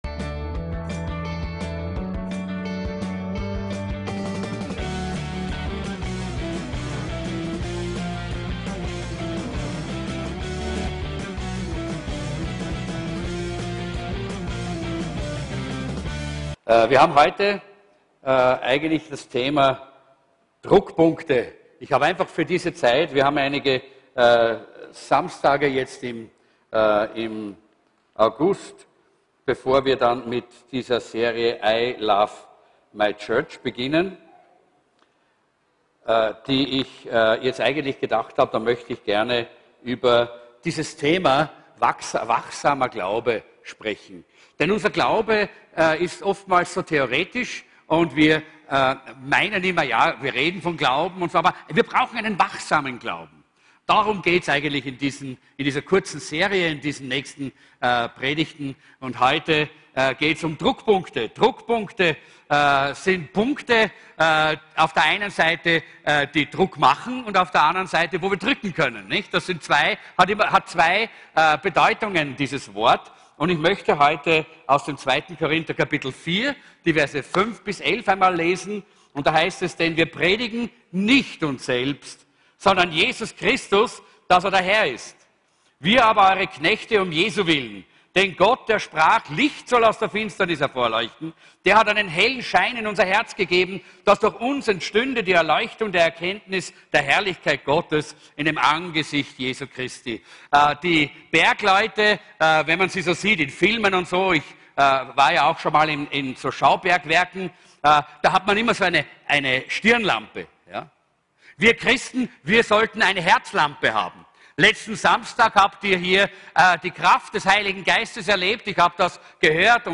WACHSAMER GLAUBE ~ VCC JesusZentrum Gottesdienste (audio) Podcast